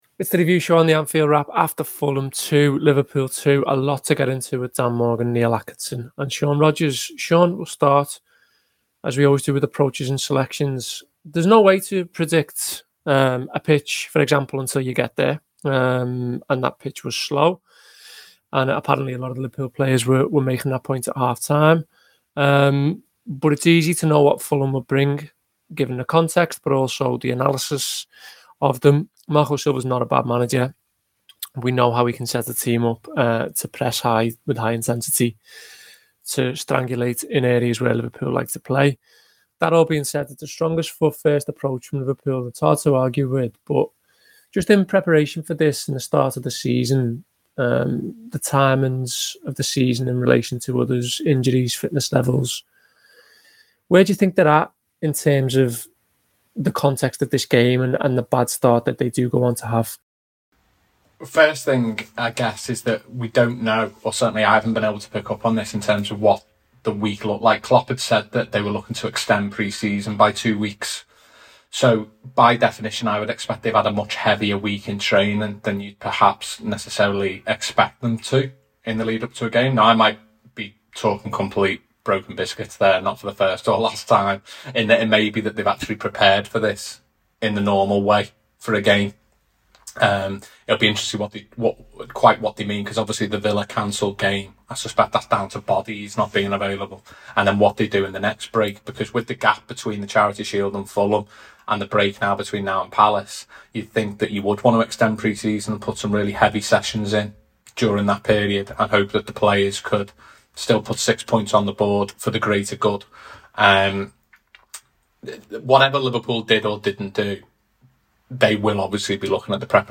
Below is a clip from the show – subscribe for more review chat around Fulham 2 Liverpool 2…